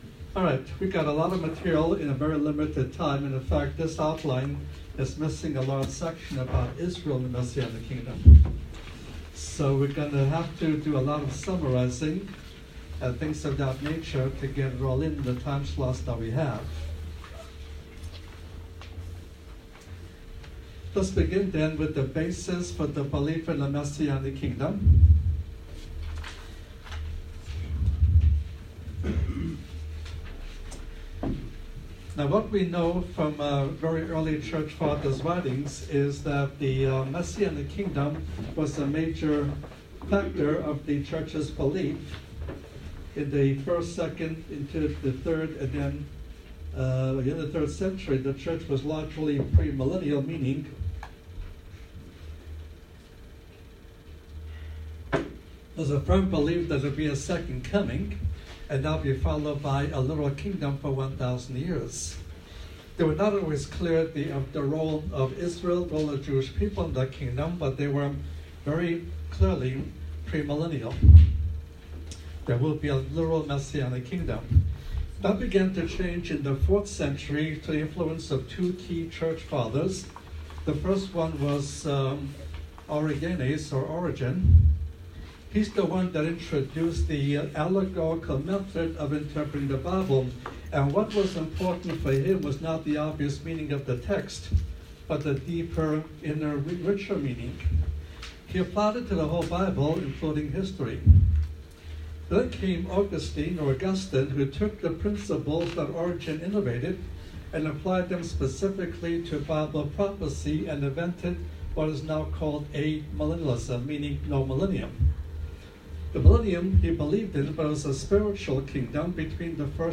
God, Lord God Most High, Bible, Christian, Christianity, Jesus Christ, Jesus, salvation, good news, gospel, messages, sermons